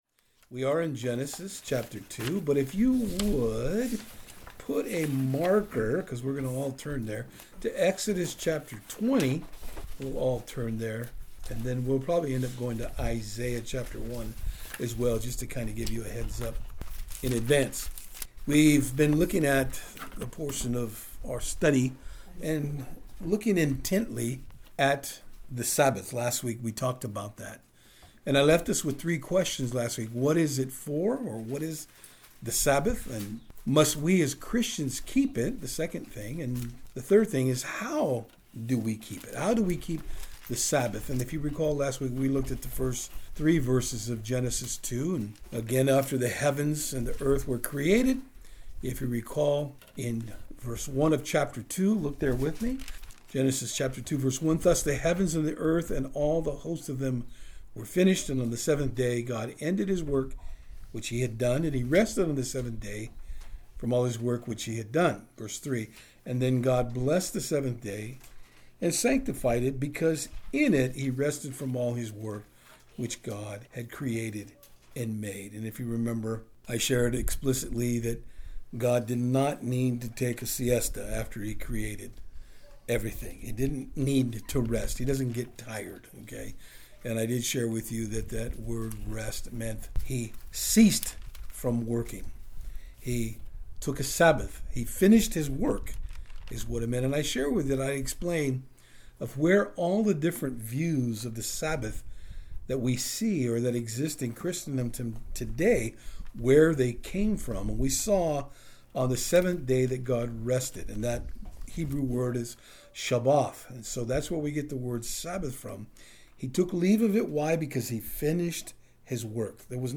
Service Type: Saturdays on Fort Hill IN our study today we will be looking at 1.